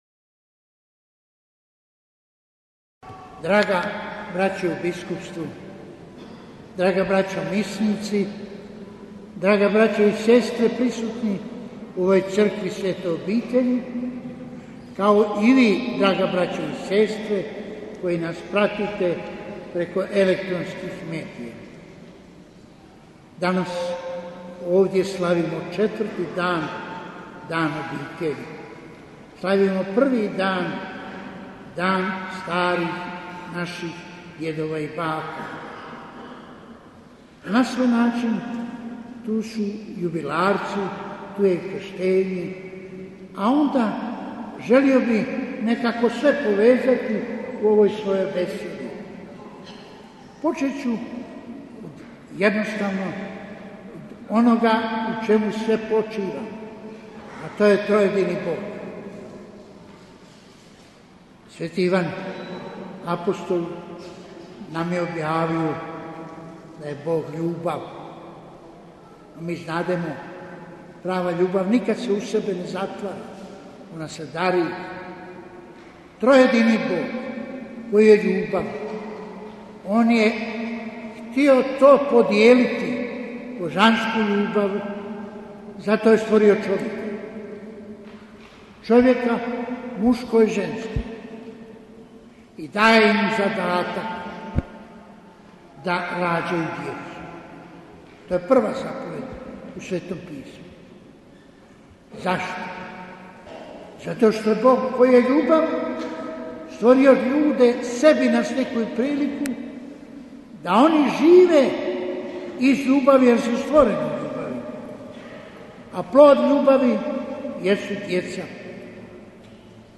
AUDIO: PROPOVIJED KARDINALA PULJIĆA NA 4. OBITELJSKI DAN I 1. SVJETSKI DAN DJEDOVA, BAKA I STARIJIH OSOBA - BANJOLUČKA BISKUPIJA
Euharistijsko slavlje predvodio je predsjednik Biskupske konferencije Bosne i Hercegovine kardinal Vinko Puljić, nadbiskup metropolit vrhbosanski, čiju propovijed prenosimo u cijelosti: